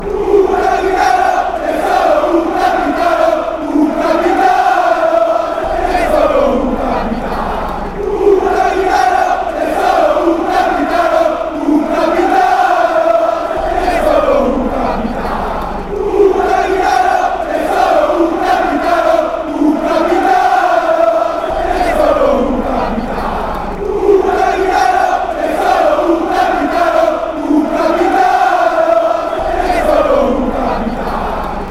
A ASB soccer chant.
Un coro dei tifosi anti Juve